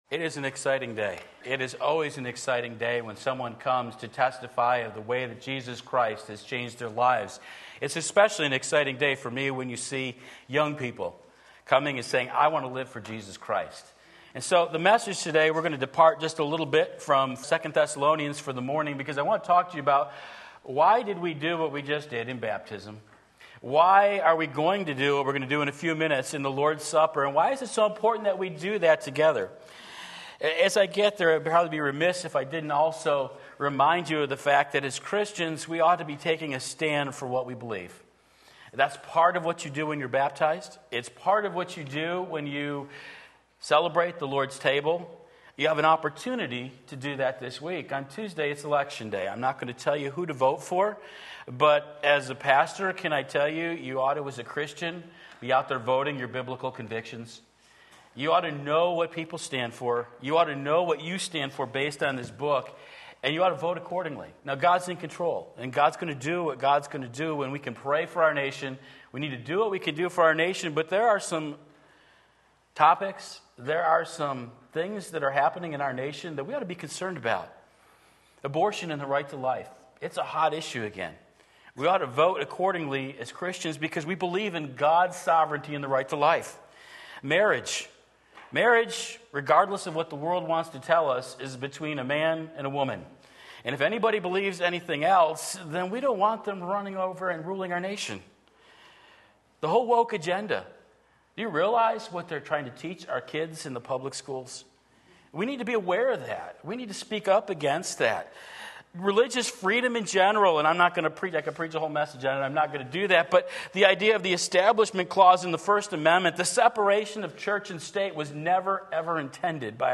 Sermon Link
1 Corinthians 11:23-32 Sunday Morning Service